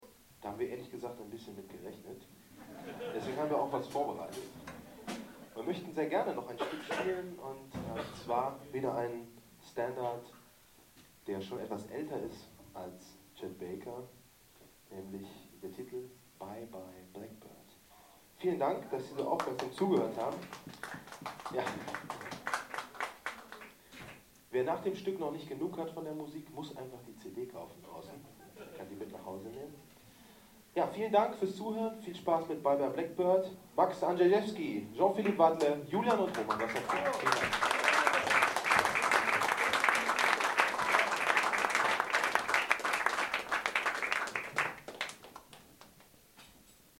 16 - Ansage.mp3